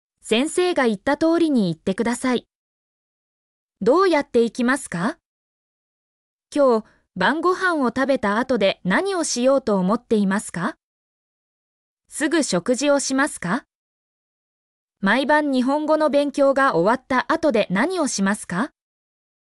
mp3-output-ttsfreedotcom-23_c3ZDZAaR.mp3